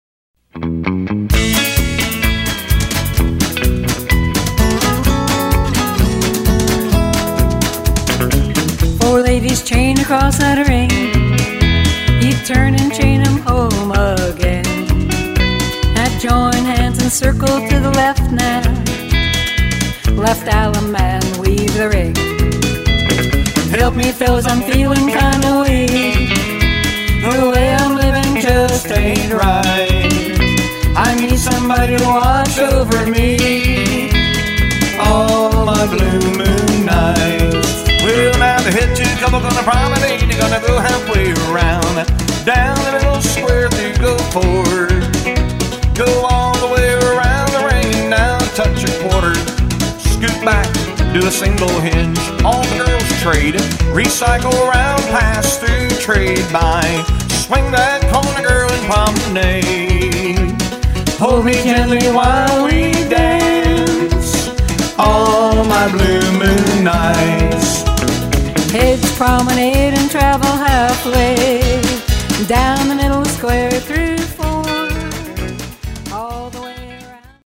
Mainstream
Instrumental